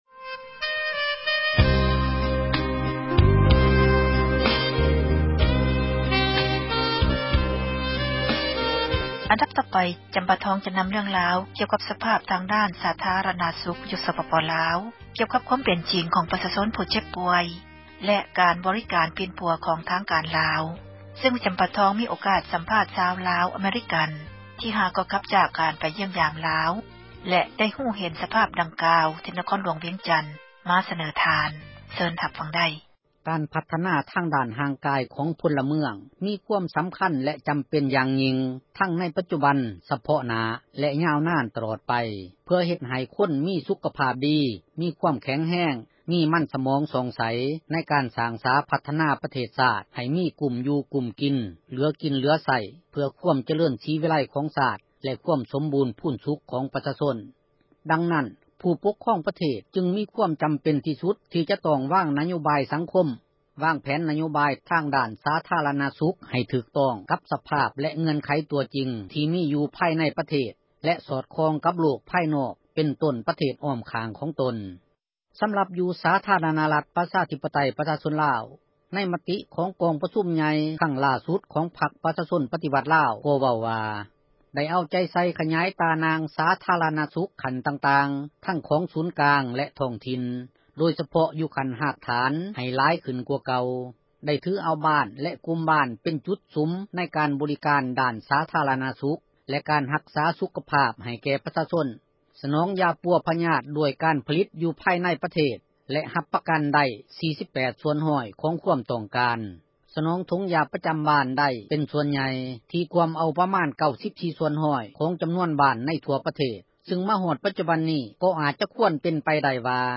ສາທາຣະນະສຸກ ໃນສປປລາວ — ຂ່າວລາວ ວິທຍຸເອເຊັຽເສຣີ ພາສາລາວ